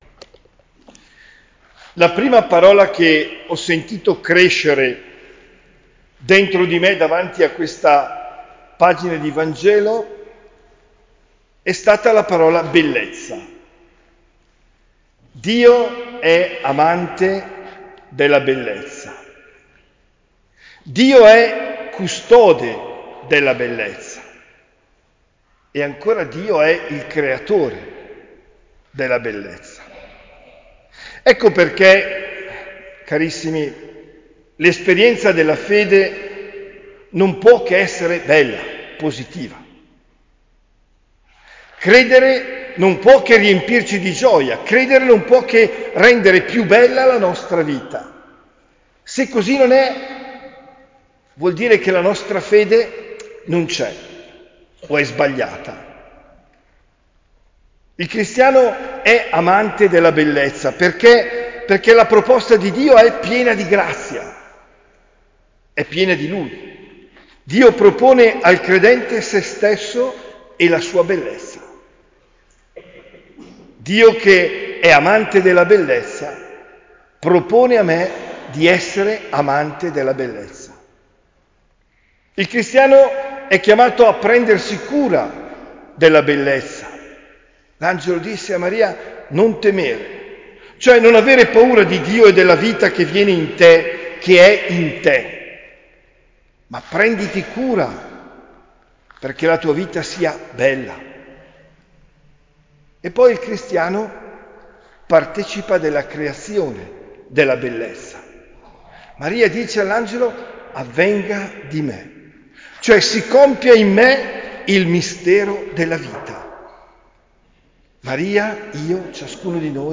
OMELIA DEL 08 DICEMBRE 2023